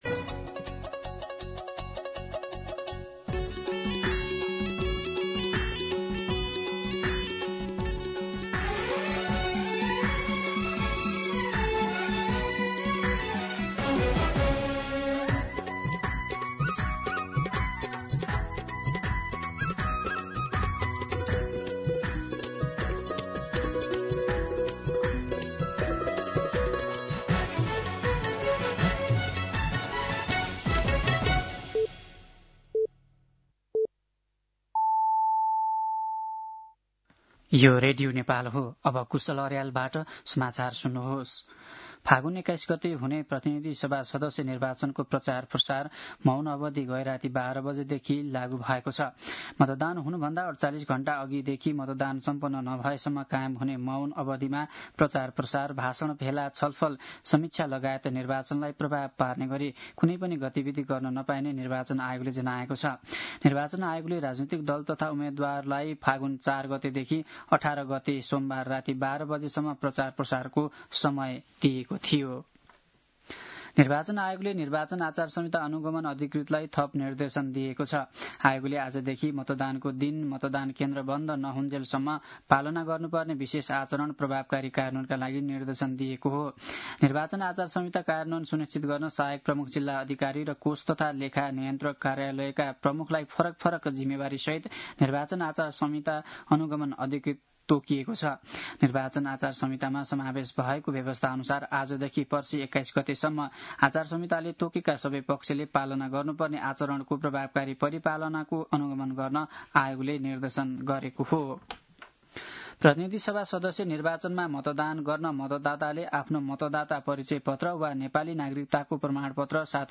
दिउँसो ४ बजेको नेपाली समाचार : १९ फागुन , २०८२
4pm-News-19.mp3